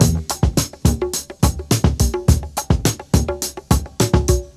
break